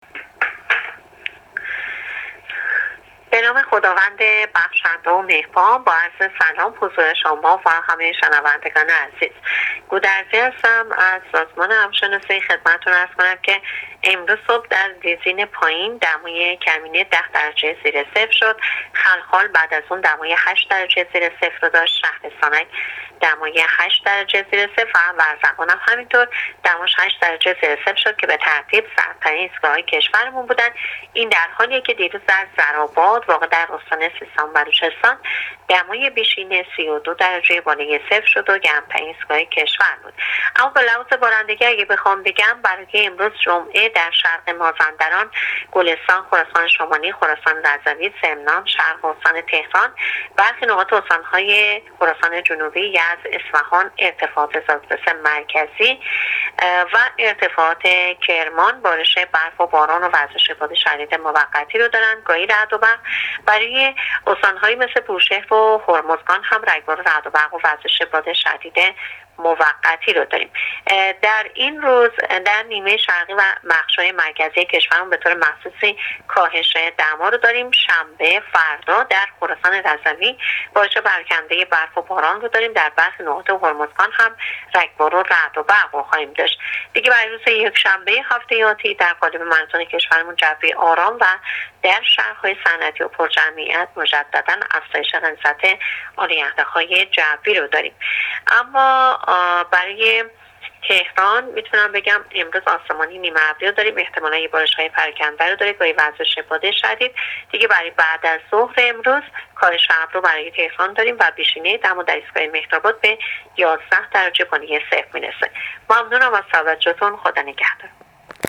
کارشناس سازمان هواشناسی کشور در گفت‌وگو با رادیو اینترنتی وزارت راه‌وشهرسازی، آخرین وضعیت آب‌و‌هوای کشور را تشریح کرد.
گزارش رادیو اینترنتی از آخرین وضعیت آب‌‌و‌‌‌هوای دوازدهم آذر؛